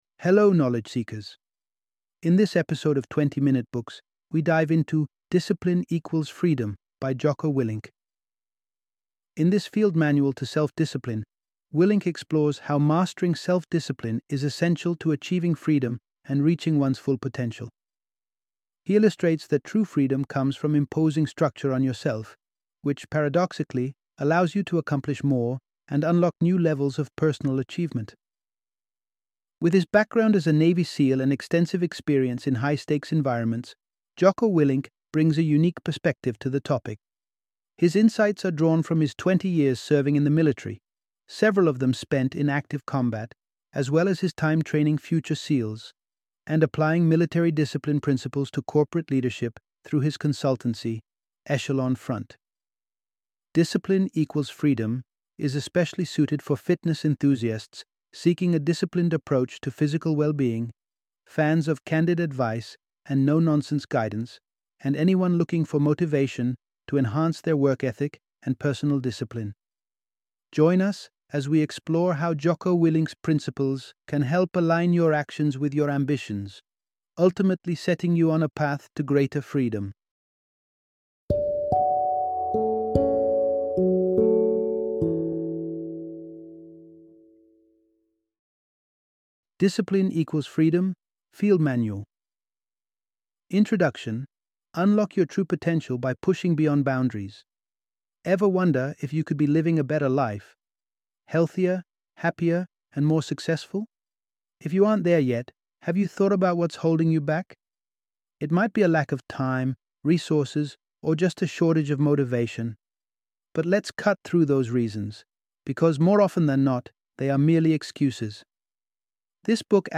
Discipline Equals Freedom - Audiobook Summary